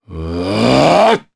Baudouin-Vox_Casting2_jp.wav